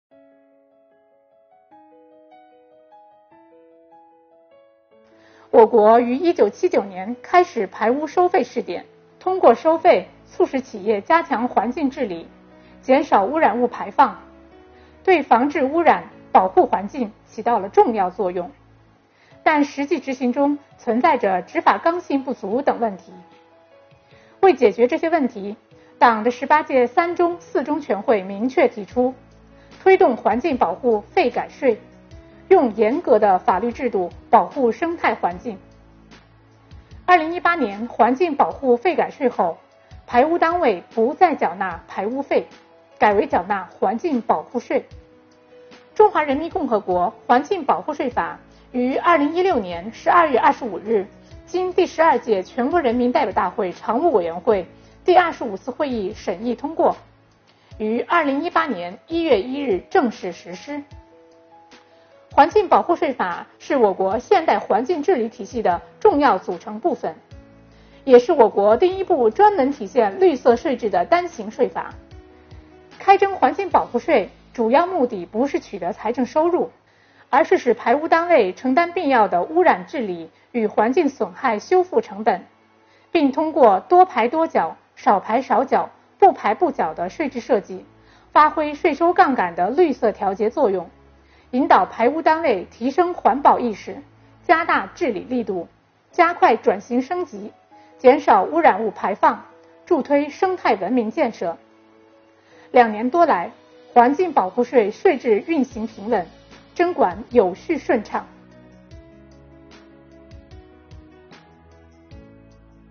在国家税务总局近期组织播出的“税务讲堂”公开课上，国家税务总局财产和行为税司副司长刘宜围绕环境保护税政策进行了详细介绍。